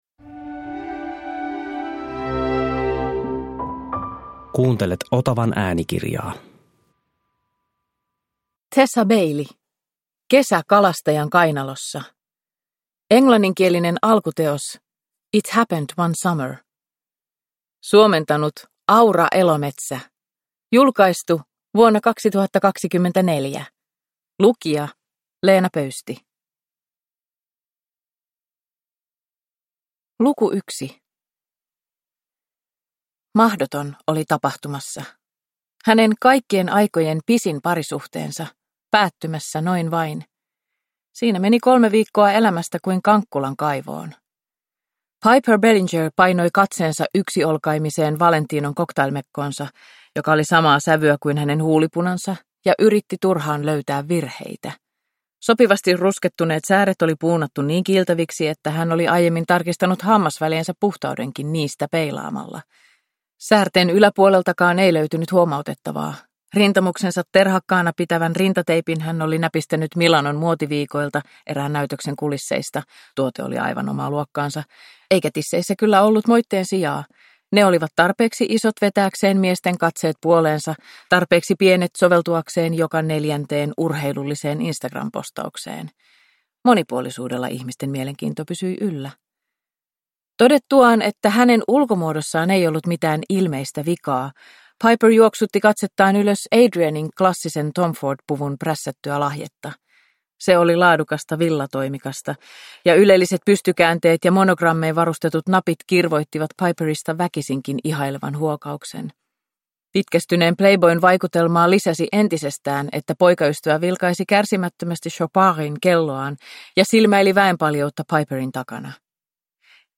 Kesä kalastajan kainalossa (ljudbok) av Tessa Bailey | Bokon